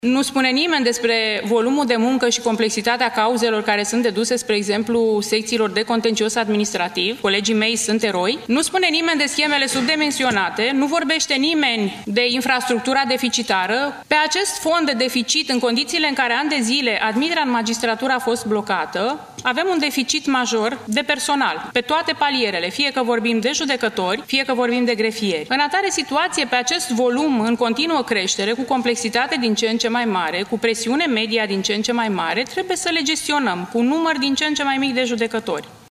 Șefa Curții de Apel București, Liana Arsenie: „Avem un deficit major de personal pe toate palierele”